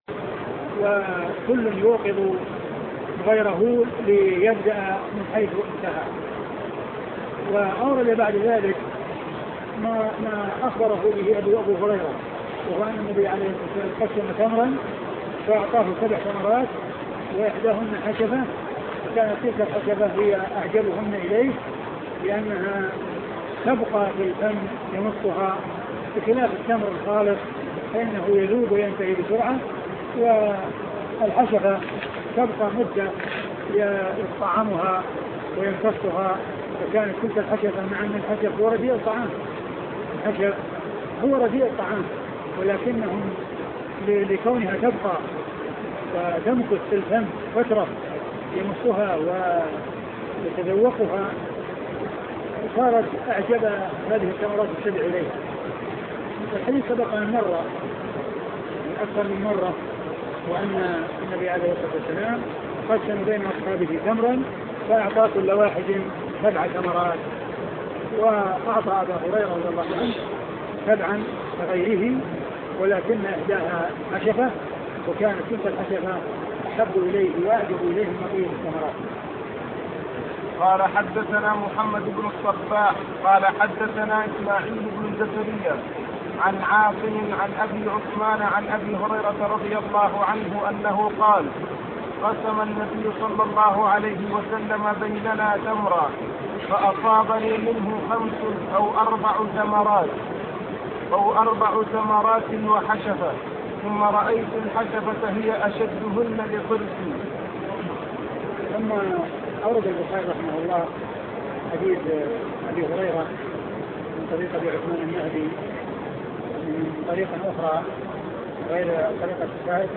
شرح صحيح البخاري الدرس عدد 536